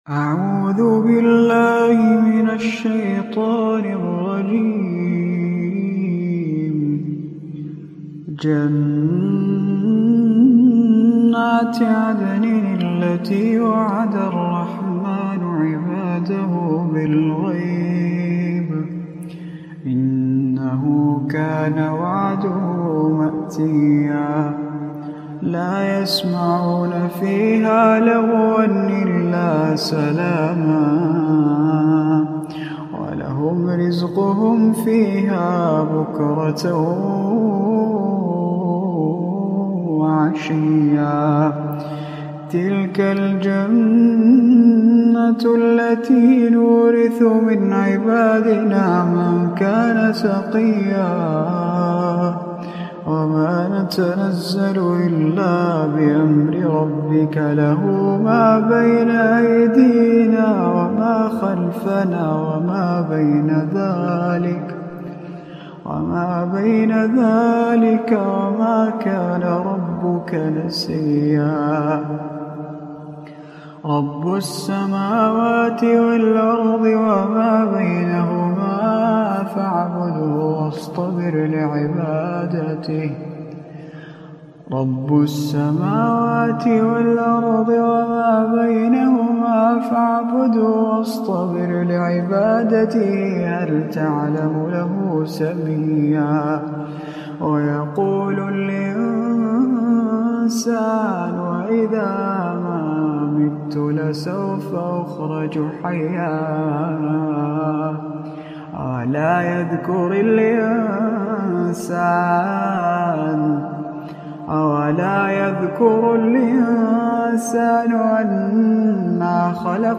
إلى جانب ذلك فهو يتمتع بصوت عذب رقيق يجعل صوته تخشع له القلوب وهو صوت لا مشابه له بين القراء.